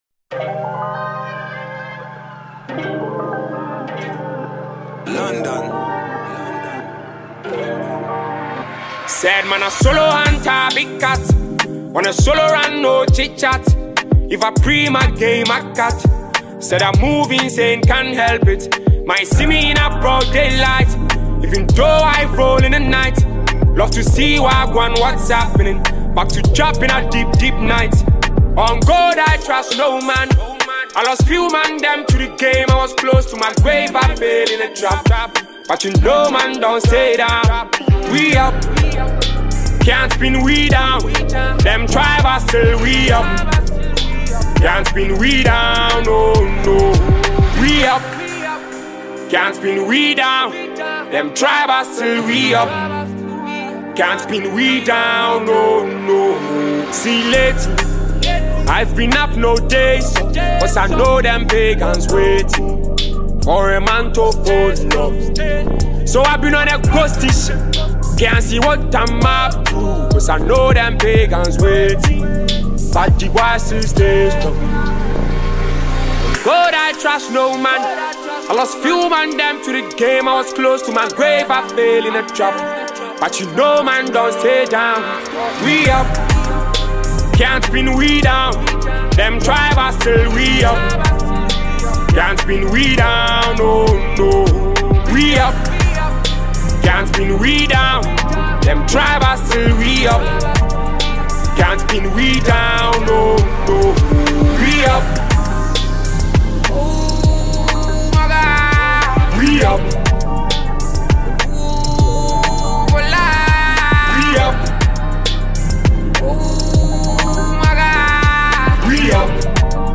a melodious sound